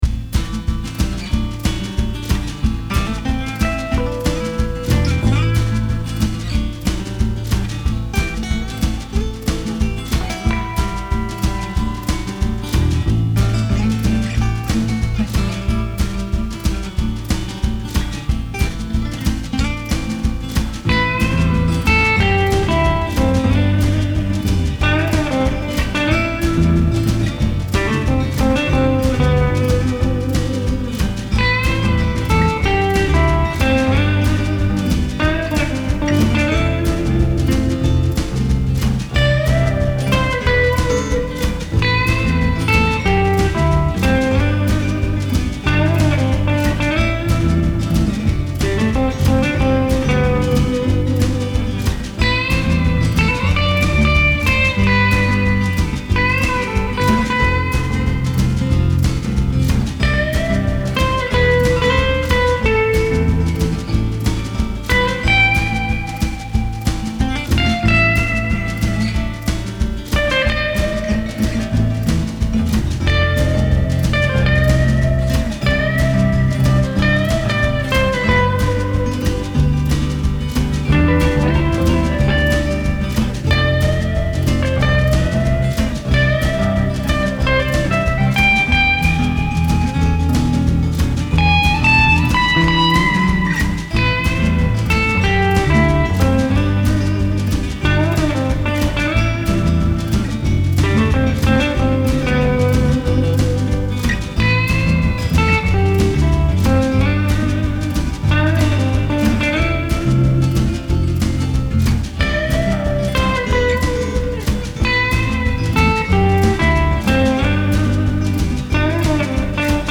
Acoustic Guitar: Yamaha APX900 Lead Guitar: Slash L Guitars “Katie May” Amp: Aracom VRX18 into a closed-back 1 X 12 with a Jensen P12N Alnico speaker.
As with most of my recordings, the lead part is not EQ’d. I let the guitar and amp do all the EQ work, then just add effects. In this case, I used a matrix reverb to get that large room sound and a sample delay to add a bit more ambiance.
Even without the modulation effects, the tone is very rich, which is why I do my best to not do too much with it in production.